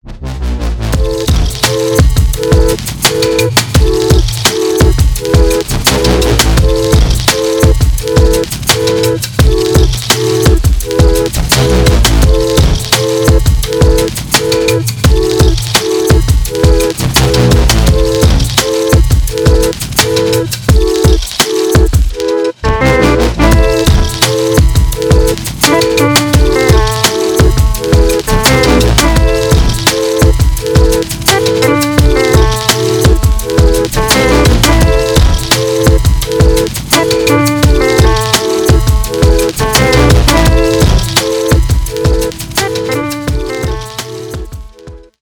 electronic , без слов
дабстеп